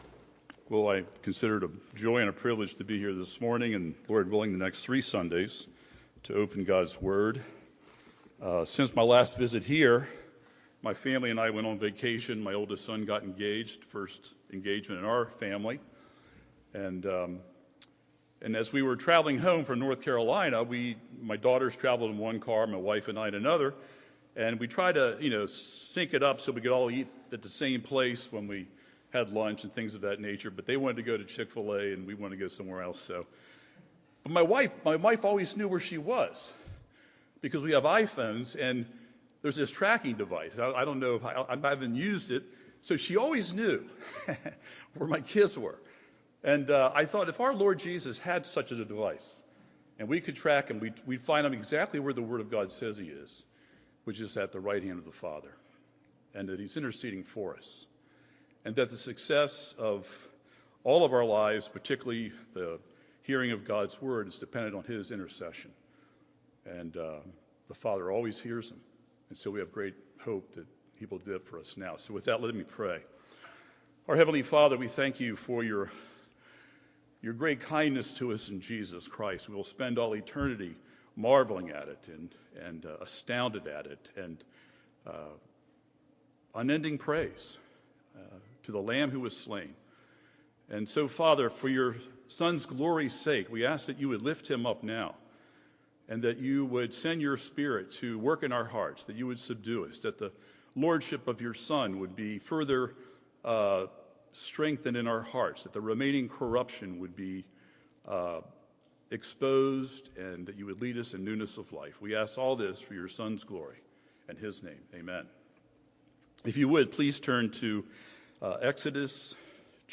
An Unenviable Situation: Sermon on Exodus 6:1-9